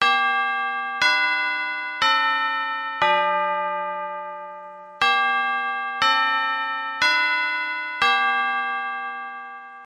колокольчики